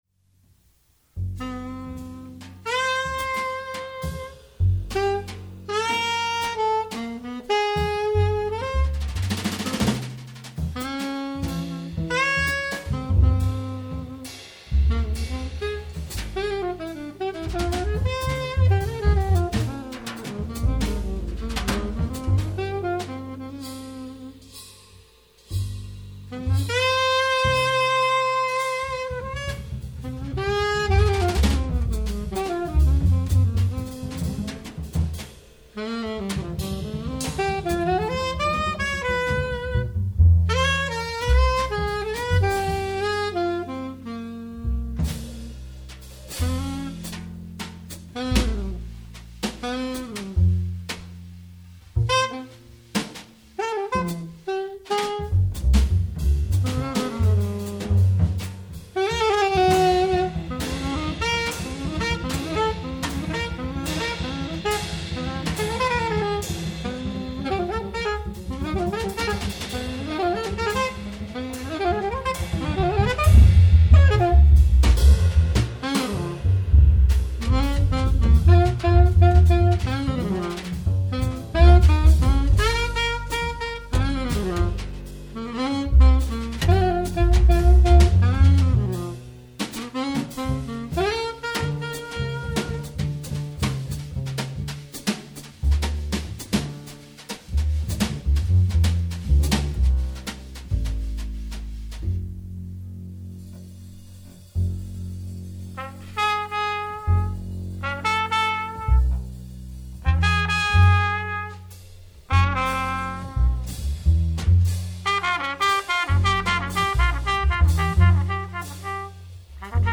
036: Quartet, Studio Rivbea, NYC, September, 1972